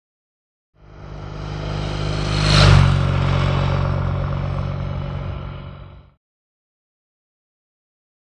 Motorcycle; By; Triumph Twin Drive Bye At 50 Mph.